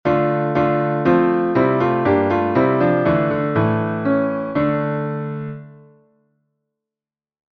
Bergerette, der Basse Dance verwandter Tanz des 16. Jahrhunderts, meist im 6/8-Takt; vgl. ein Beispiel von 1551 (Susato):